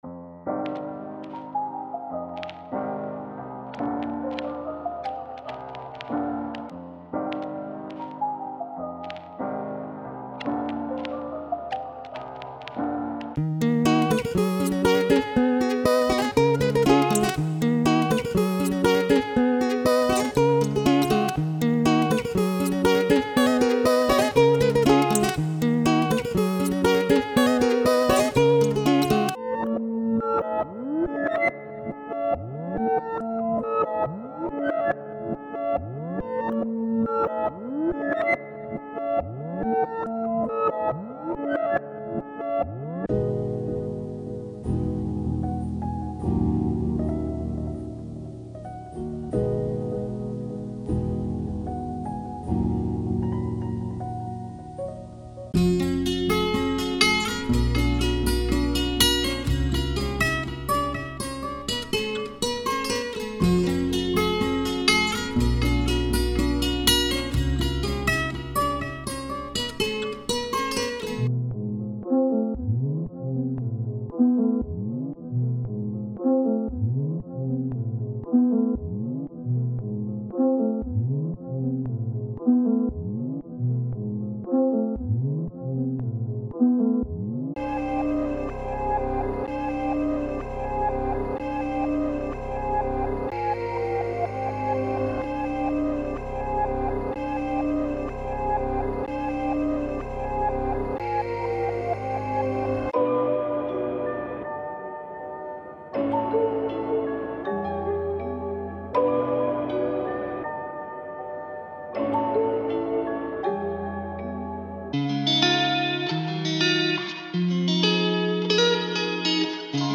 所有音频文件均经过专业混音和母带处理。